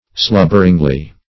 Search Result for " slubberingly" : The Collaborative International Dictionary of English v.0.48: Slubberingly \Slub"ber*ing*ly\, adv. In a slovenly, or hurried and imperfect, manner.